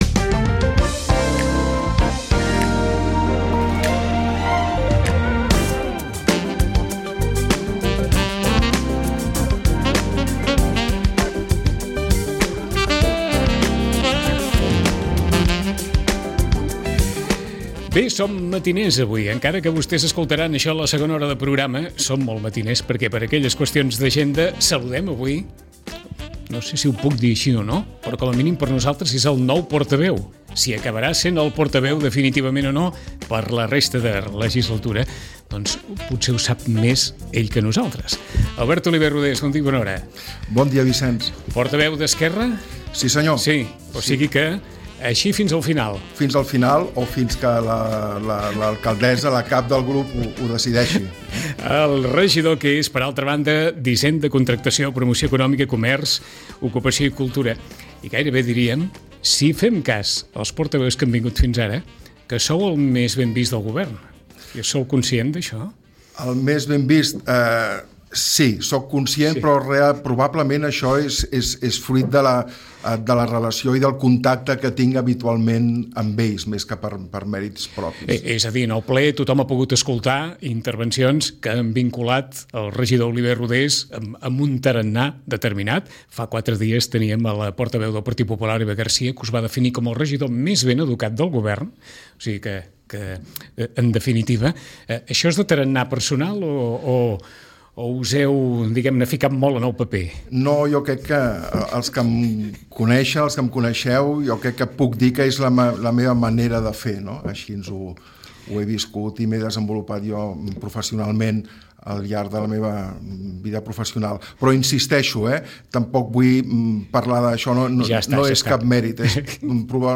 Saludem al nou portaveu d’ERC, que és el regidor d’hisenda, contractació, promoció econòmica, comerç i cultura, Albert Oliver-Rodés. Oliver ha lamentat que no s’hagin pogut aprovar l’actualització de les ordenances fiscals i reconeix que no seran fàcils les negociacions per a tenir pressupost per al 2026.